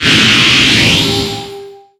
Cri de Mouscoto dans Pokémon Soleil et Lune.